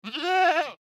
Minecraft Version Minecraft Version snapshot Latest Release | Latest Snapshot snapshot / assets / minecraft / sounds / mob / goat / idle2.ogg Compare With Compare With Latest Release | Latest Snapshot